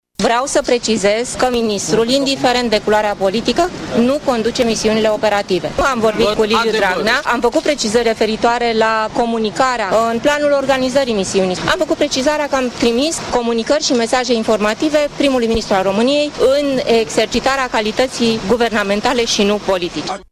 Fostul ministru de interne Carmen Dan a declarat astăzi la ieșirea de la audierile de la DIICOT că nu a avut un rol activ de coordonare în cadrul evenimentelor din 10 august anul trecut din Piața Victoriei din București.